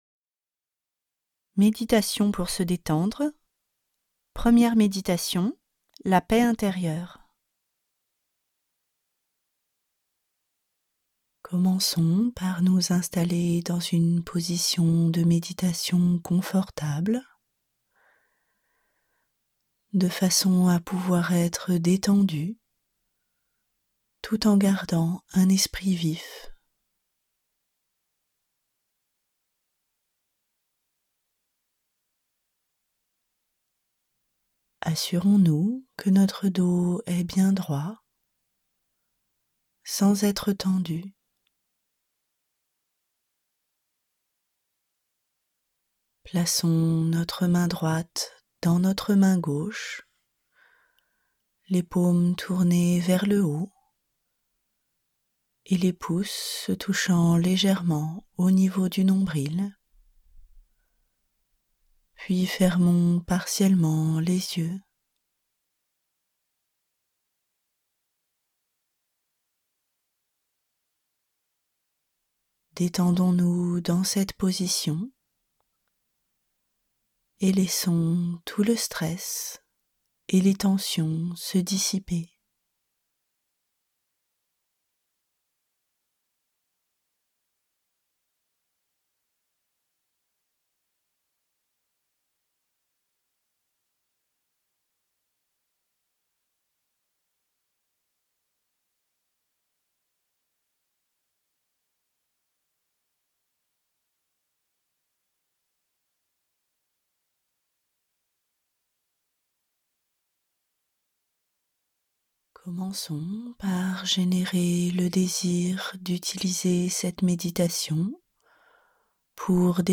Méditations pour se détendre Trois méditations guidées pour détendre le corps et l'esprit